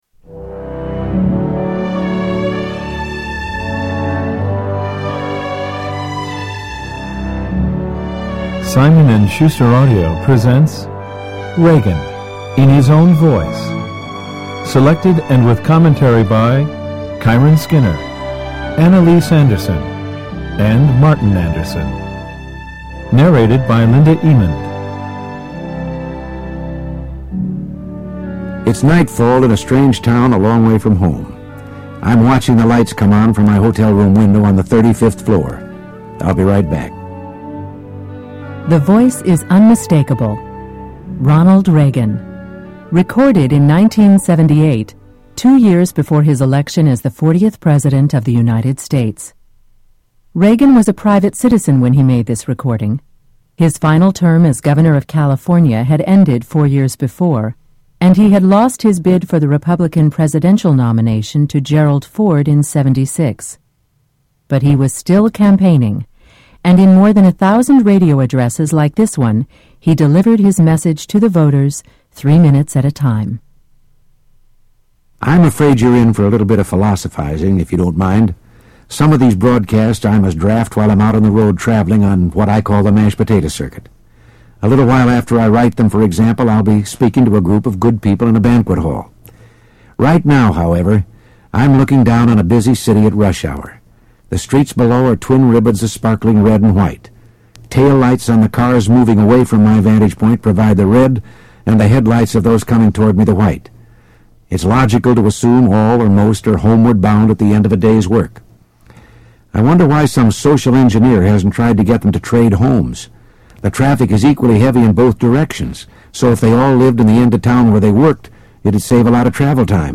Reagan in His Own Voice 1